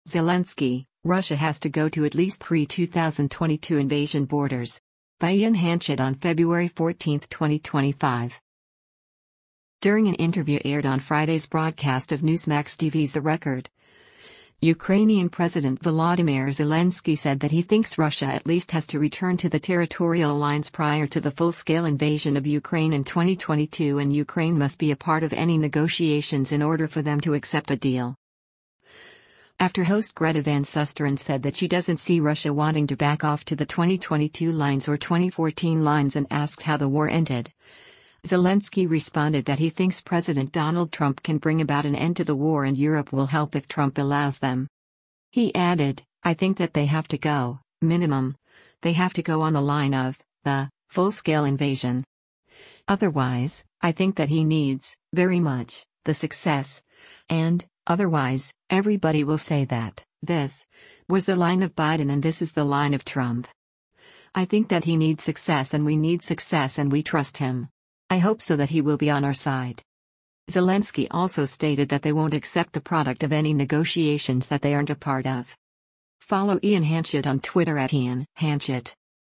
During an interview aired on Friday’s broadcast of Newsmax TV’s “The Record,” Ukrainian President Volodymyr Zelensky said that he thinks Russia at least has to return to the territorial lines prior to the full-scale invasion of Ukraine in 2022 and Ukraine must be a part of any negotiations in order for them to accept a deal.